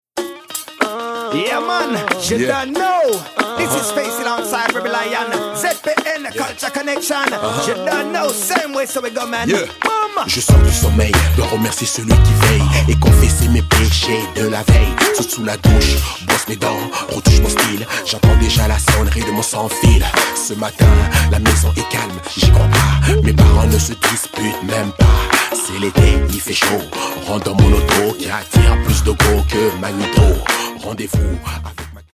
basse
guitare
clavier